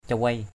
/ca-weɪ:/ (d.) chim khách (hỷ thước) = Photodytes tristis. cawei ak cw] aK chim khách màu đen.